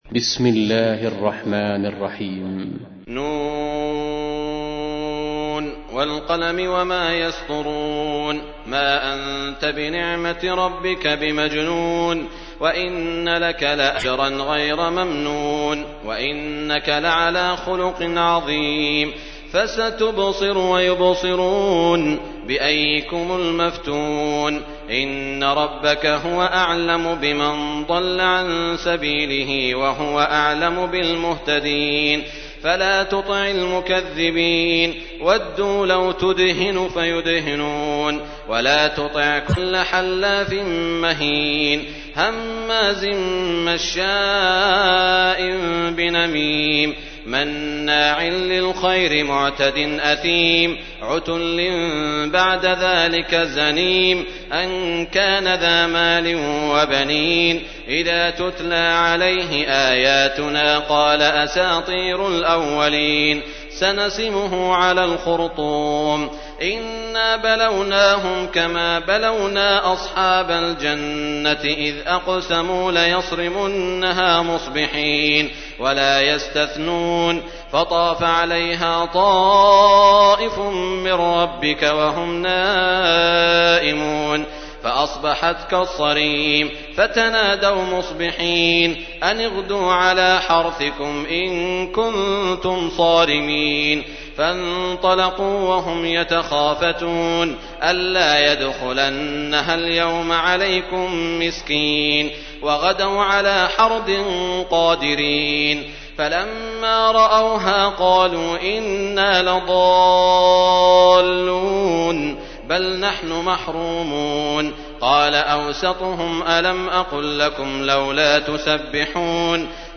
تحميل : 68. سورة القلم / القارئ سعود الشريم / القرآن الكريم / موقع يا حسين